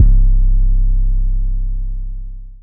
spinz 808.wav